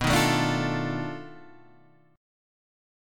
B 7th Sharp 9th Flat 5th